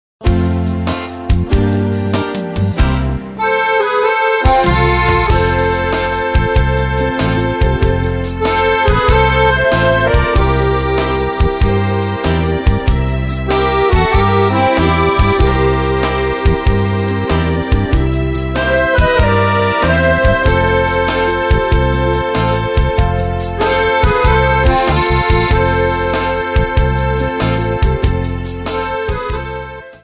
Accordion Music 3 CD Set.